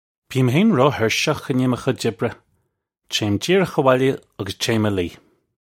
Pronunciation for how to say
Bee-im hayn roe-hirsh-ukh in yay muh khudge ibbre. Chayim jeer-ukh awolya uggus chayim uh lee. (U)
This is an approximate phonetic pronunciation of the phrase.